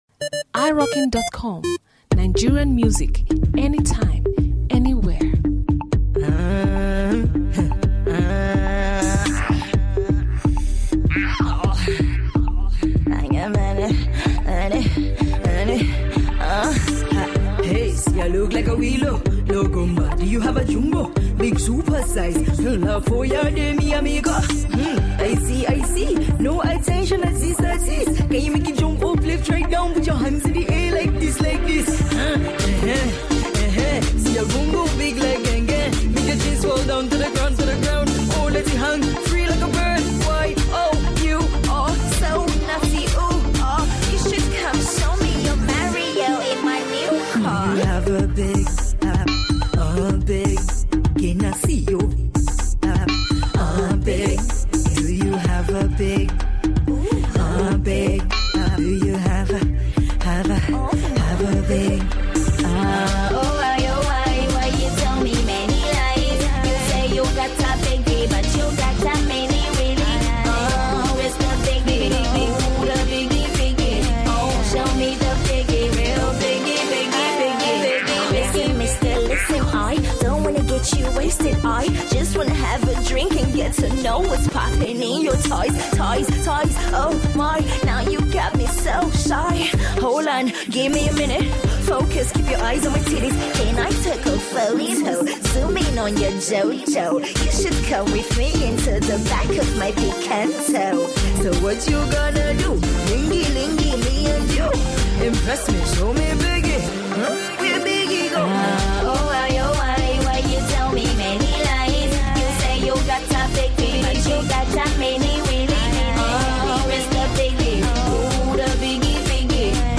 top notch Naija Femcee